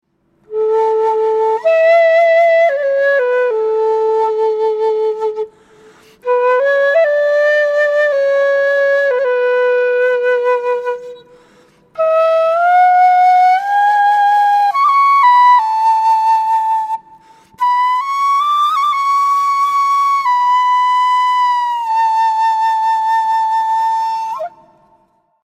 Кена (Quena, Ramos, G) Перу
Кена (Quena, Ramos, G) Перу Тональность: G
Материал: тростник
Кена - продольная флейта открытого типа, распространённая в южноамериканских Андах.
Современная кена является хроматическим инструментом c диапазоном в три октавы, на котором можно с успехом исполнять не только народную музыку Анд, но и джаз, классику и любую другую музыку.